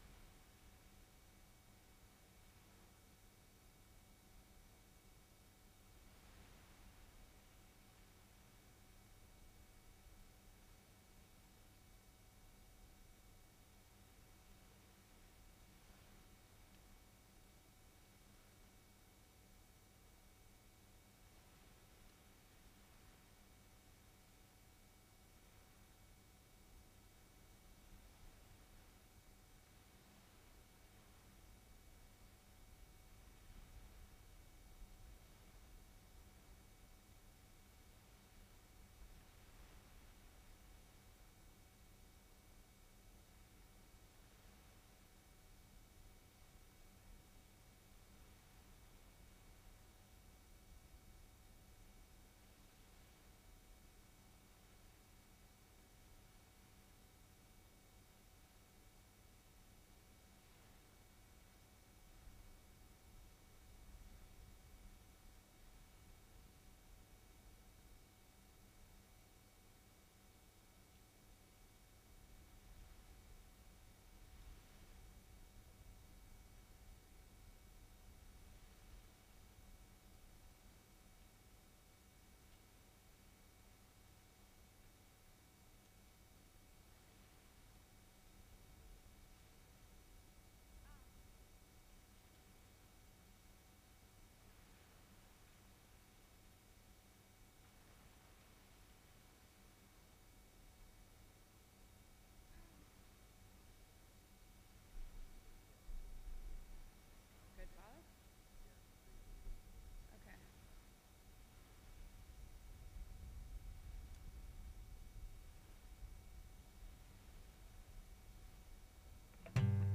Sermon Description In this passage we learn how far Abraham’s faith has grown as he trusts God to provide a wife for Isaac.